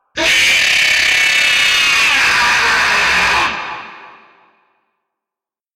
Tron Sonic Jumpscares Sound 2 Bouton sonore